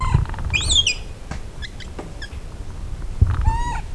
來聽動物鳴唱 -陸域哺乳類 - 台灣野生動物之聲資訊網